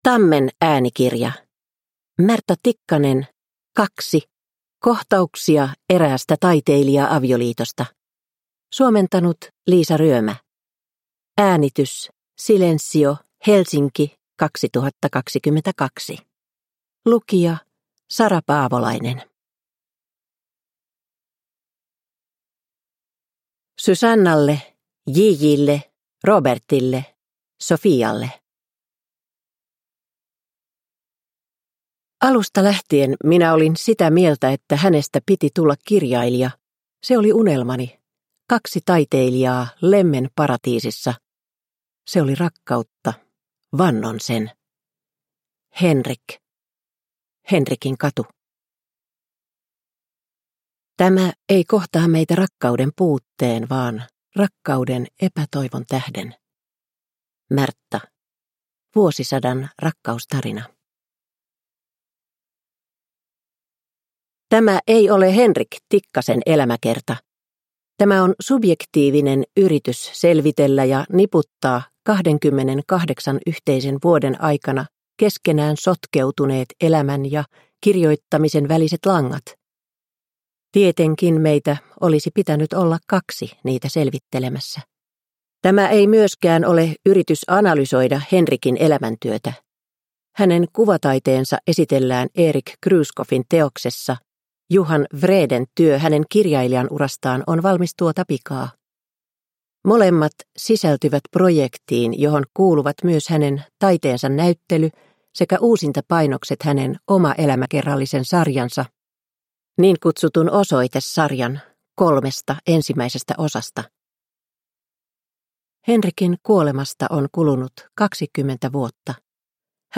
Kaksi – Ljudbok – Laddas ner